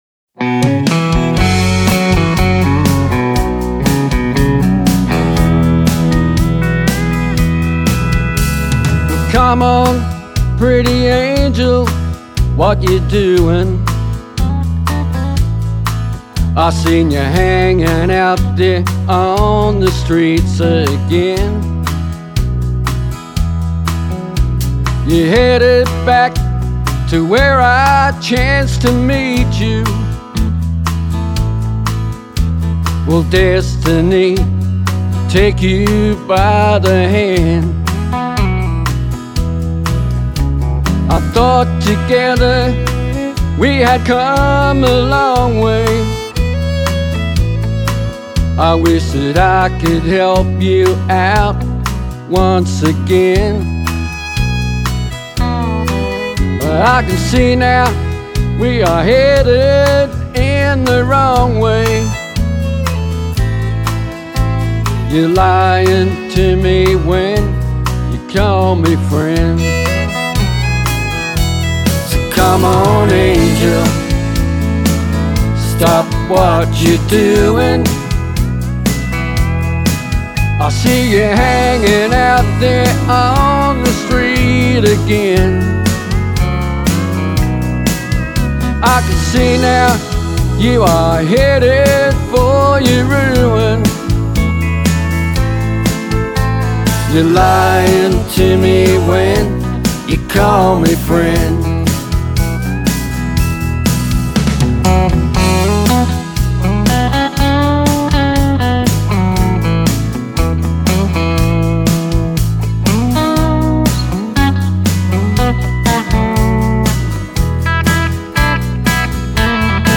country radio single
hillbilly hut studio at Cooranbong NSW.
drums
bass
pedal steele guitar
fiddle
keyboards
guitars
The song also features great harmony vocals
harmony sound and a strong traditional country influence.